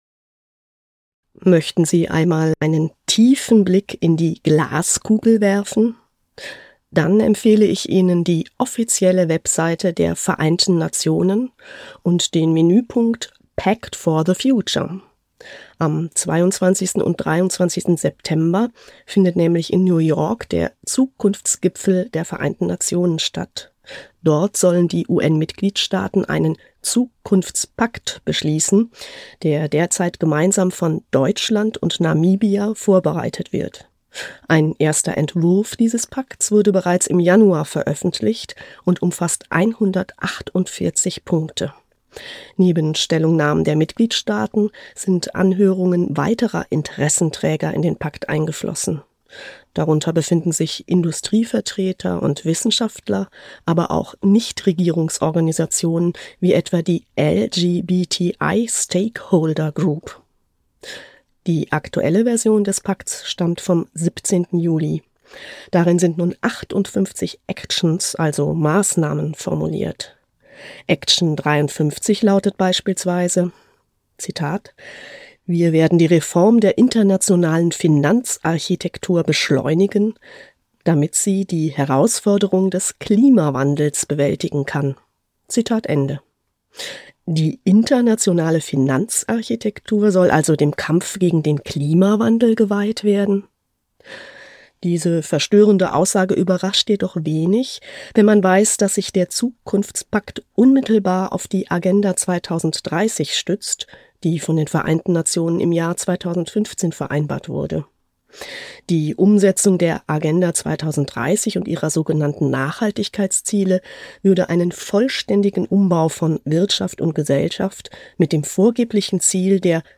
Kontrafunk-Kommentar